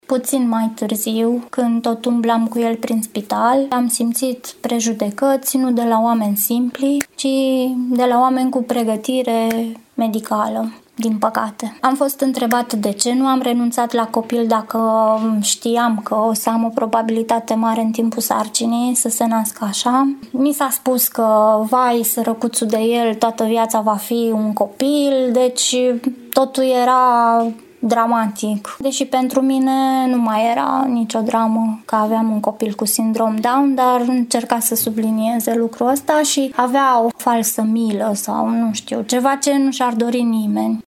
Ce simte o mamă cu un copil care are acest sindrom: